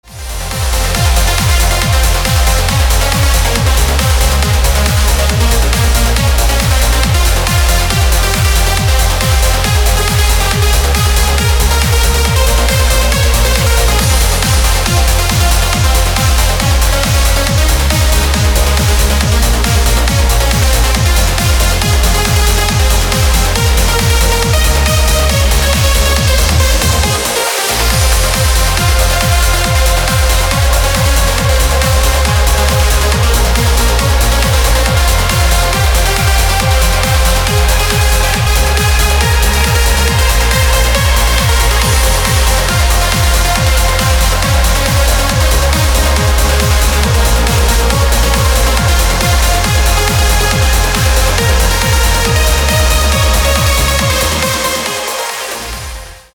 • Качество: 256, Stereo
громкие
dance
Electronic
EDM
без слов
Trance
быстрые